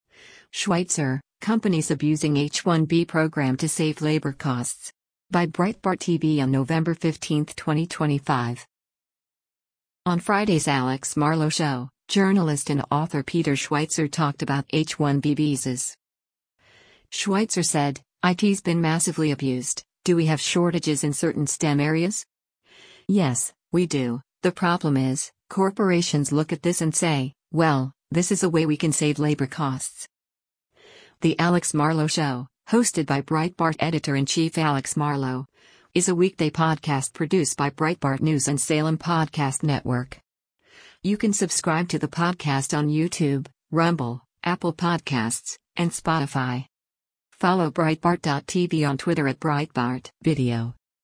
On Friday’s “Alex Marlow Show,” journalist and author Peter Schweizer talked about H-1B visas.